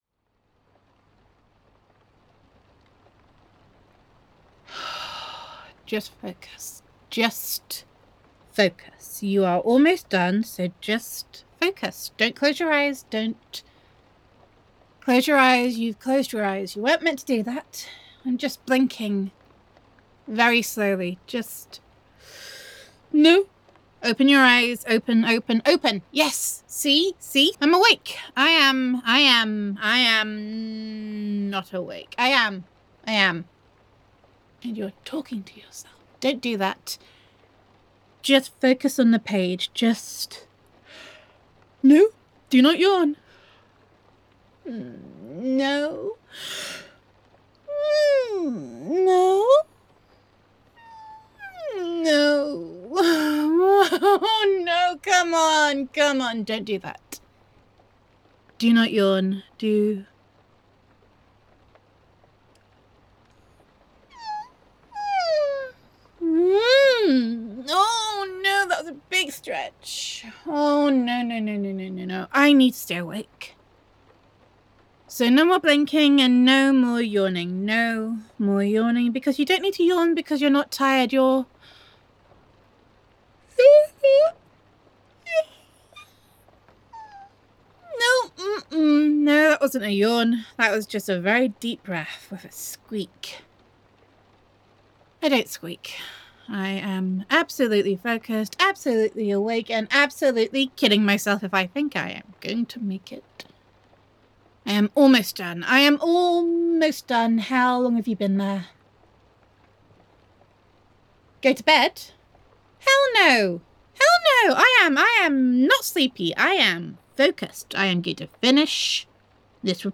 [F4A] Do Not Assume the Napping Position [Girlfriend Roleplay]
[Yawns Galore]